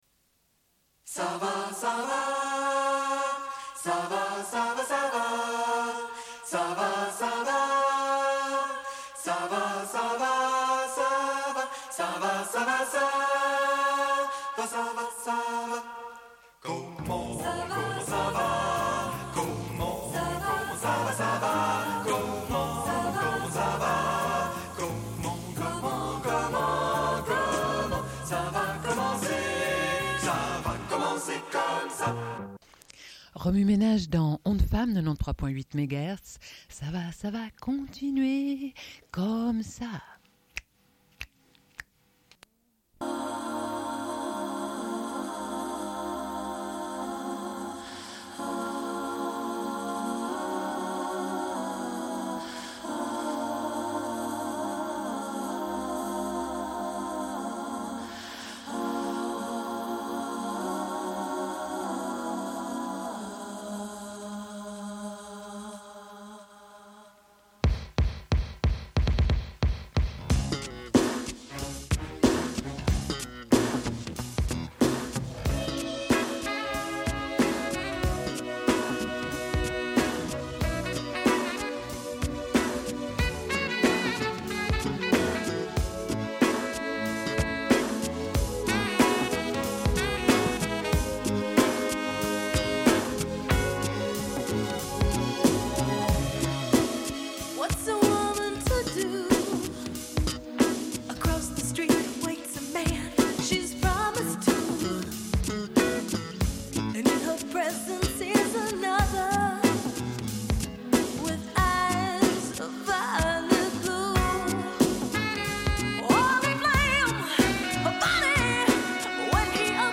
Suite de l'émission : chronique « Voix humaine », musique ou lecture. Cette semaine, émission musicale.
Une cassette audio, face A